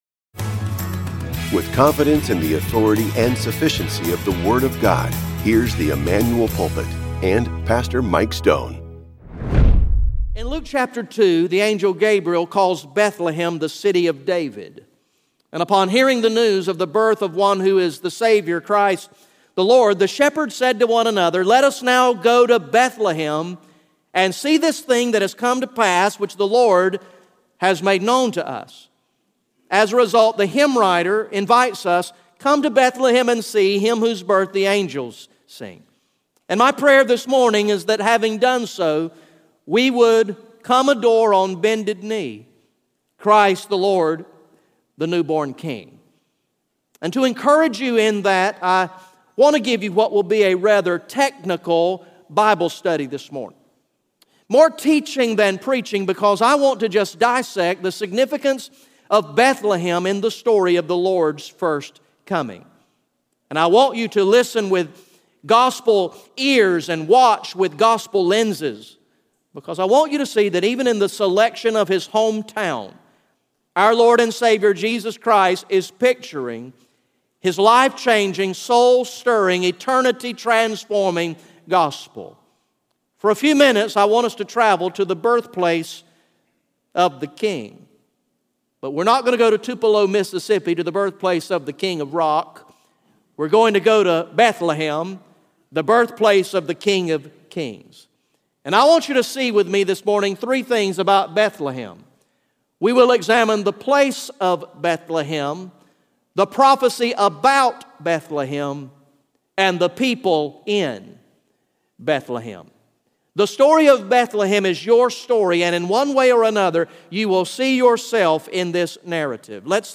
GA Message #03 from the sermon series entitled “King of Kings